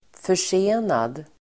Uttal: [för_s'e:nad]